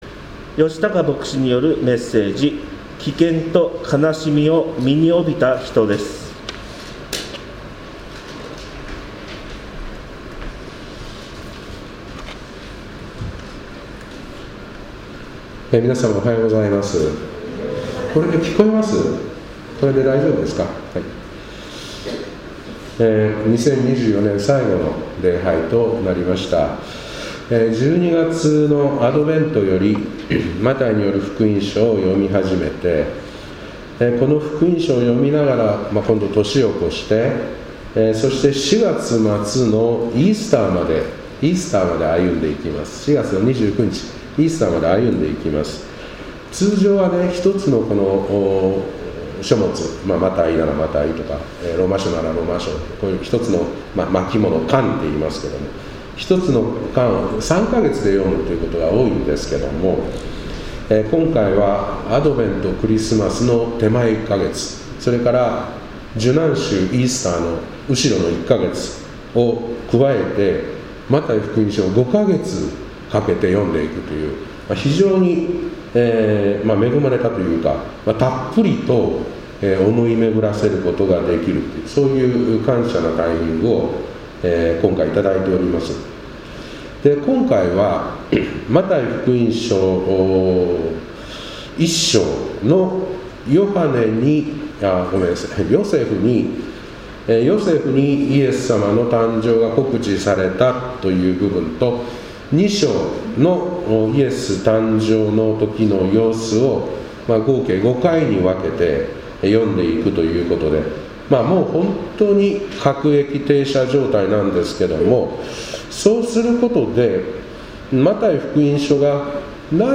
2024年12月29日礼拝「危険と悲しみを身に帯びた人」